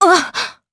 Seria-Vox_Damage_jp_01.wav